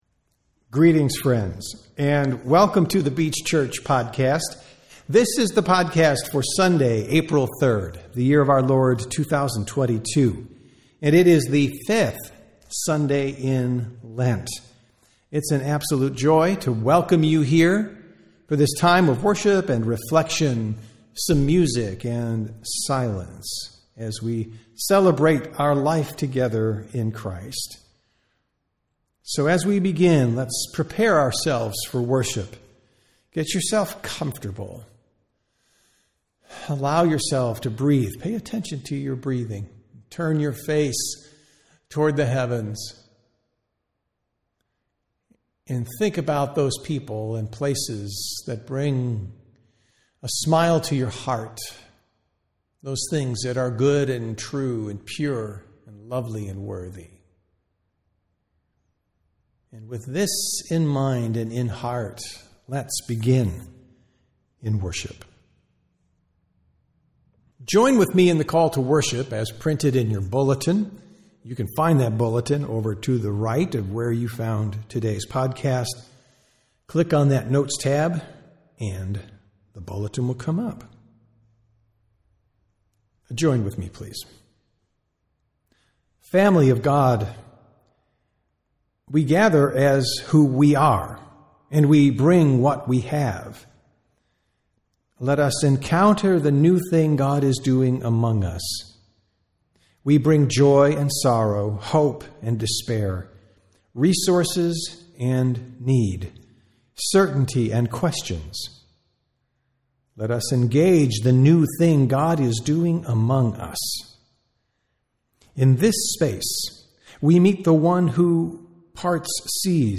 Sunday Worship - April 6, 2025